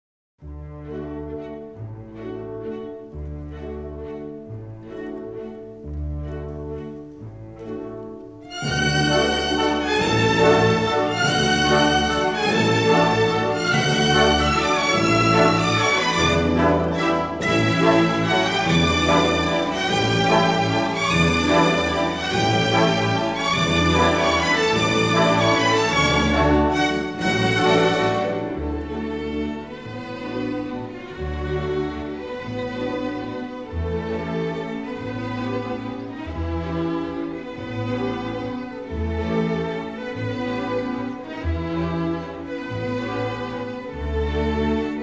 a simple, heartfelt theme for cimbalom and eerie
newly remastered from the best possible stereo sources